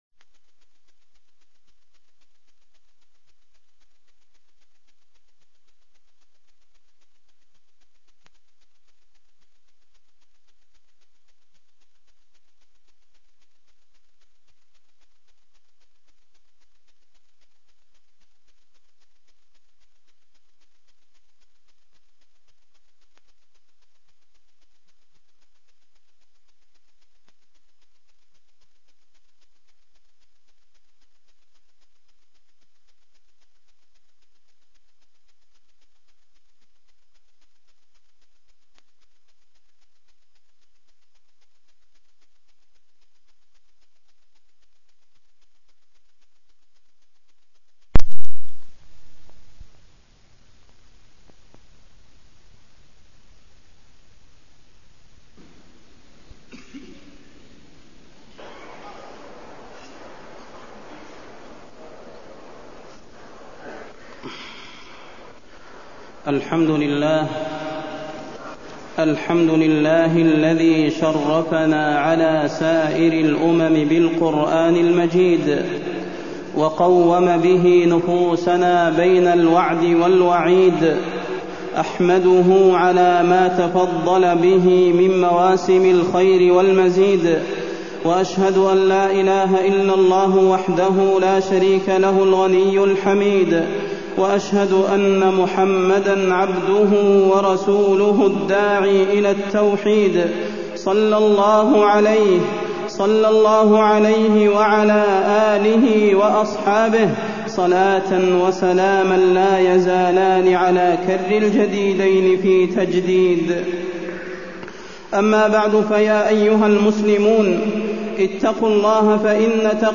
تاريخ النشر ٢٨ شعبان ١٤٢٤ هـ المكان: المسجد النبوي الشيخ: فضيلة الشيخ د. صلاح بن محمد البدير فضيلة الشيخ د. صلاح بن محمد البدير استقبال شهررمضان